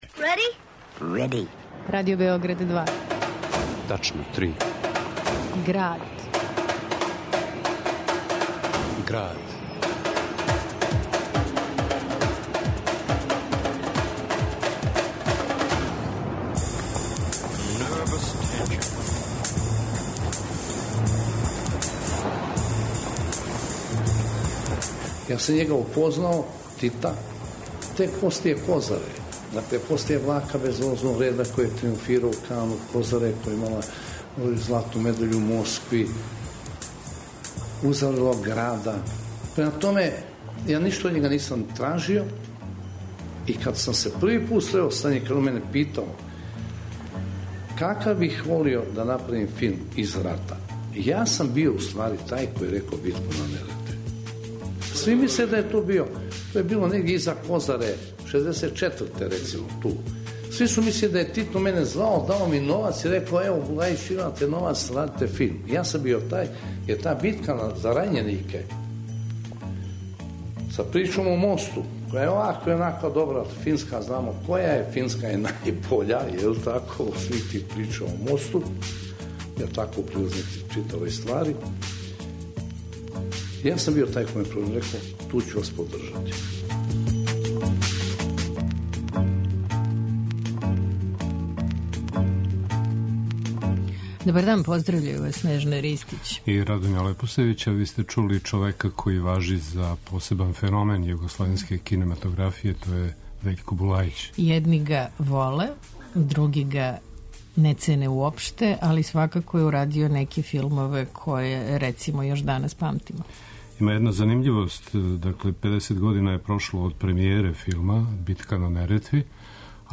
У Граду, о филму Битка на Неретви. На снимцима из 2002